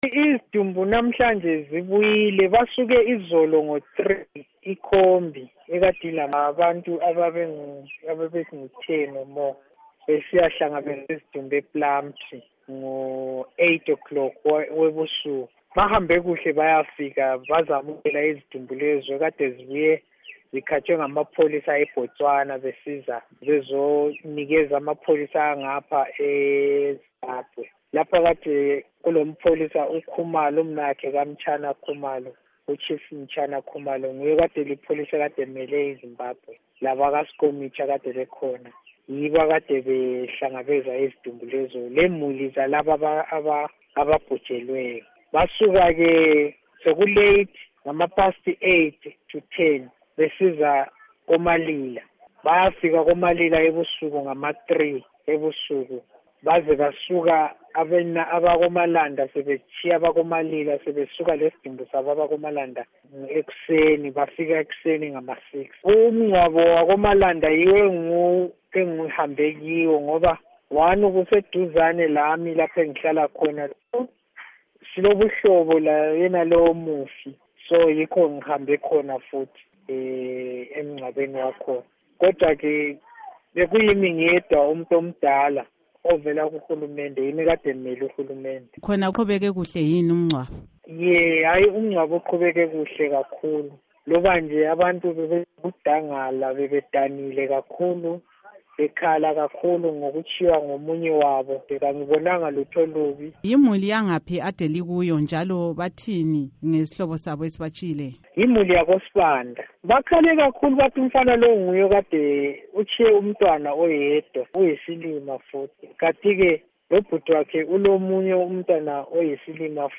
Ingxoxo LoChief Gampu Sithole